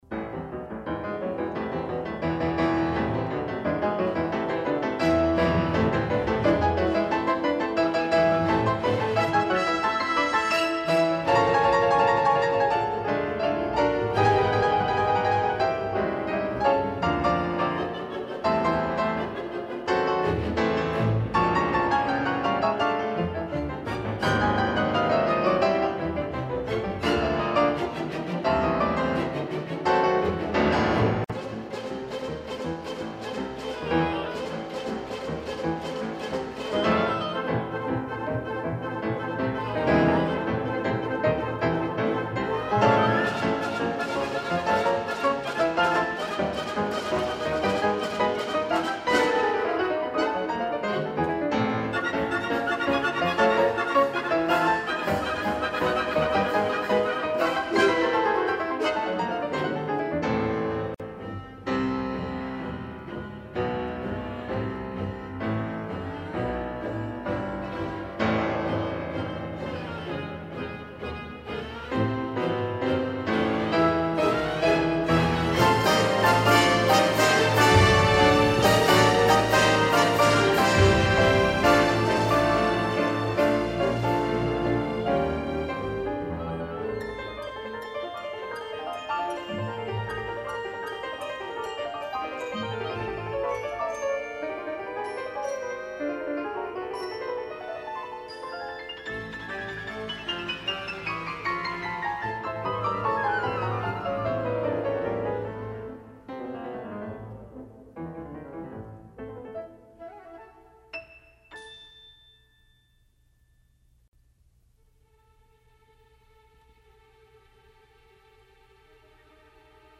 这首为钢琴与乐队而作的狂想曲OP.43作于1934年，主题选自帕格尼尼第24首随想曲（A小调），共24段变奏。